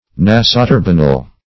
Search Result for " nasoturbinal" : The Collaborative International Dictionary of English v.0.48: Nasoturbinal \Na`so*tur"bi*nal\, a. [Naso- + turbinal.]
nasoturbinal.mp3